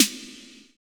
Index of /90_sSampleCDs/Roland - Rhythm Section/DRM_Drum Machine/DRM_Cheese menus